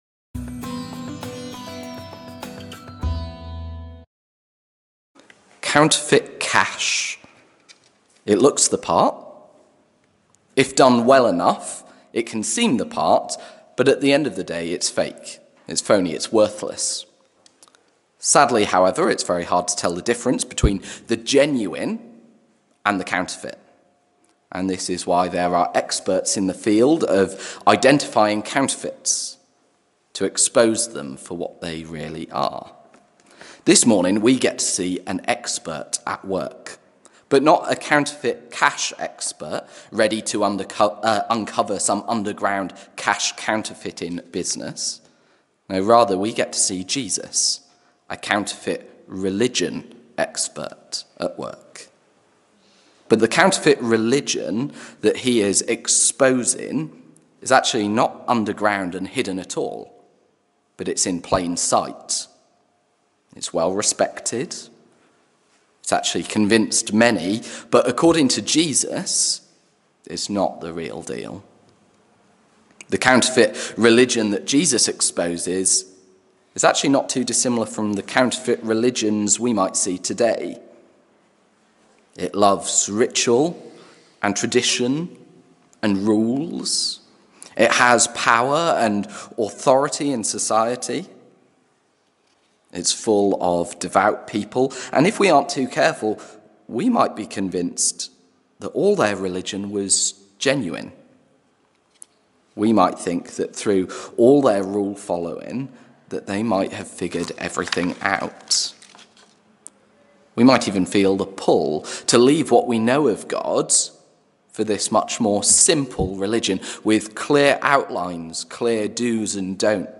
Sermons | Trinity Lancaster